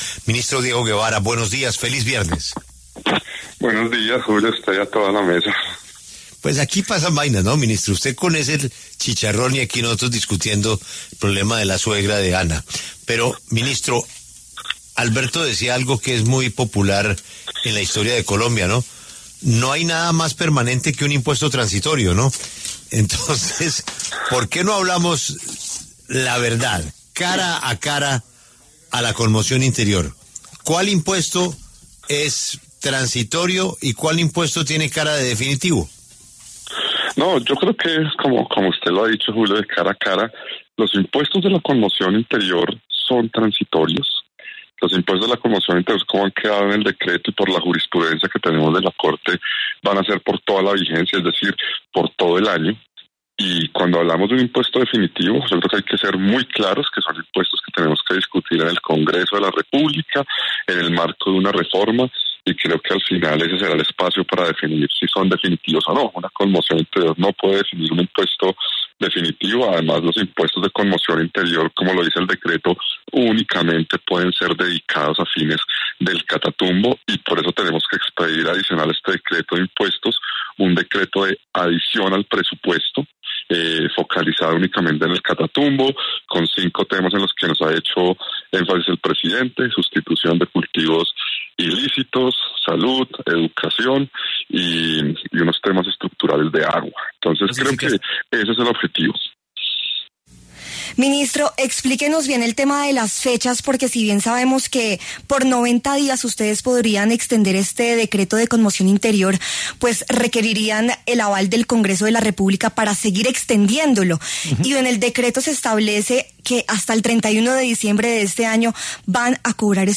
El ministro de Hacienda, Diego Guevara, explicó en La W el alcance de medidas como el IVA del 19% a juegos de suerte y azar en línea, 1% al timbre y 1% a la extracción de petróleo e hidrocarburos en el marco del estado de conmoción interior.